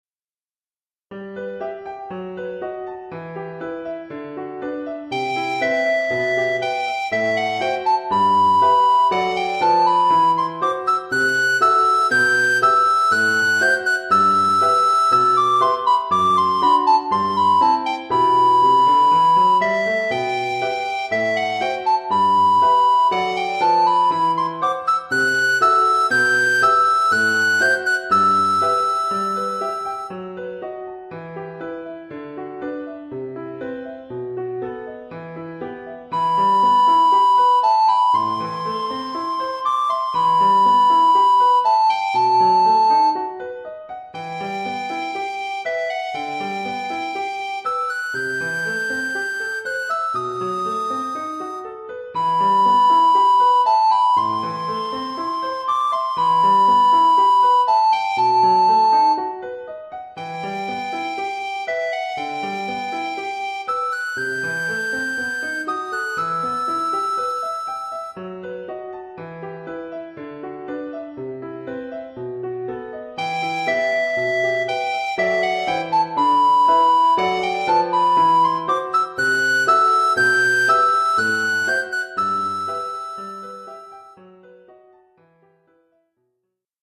Oeuvre pour flûte à bec soprano et piano.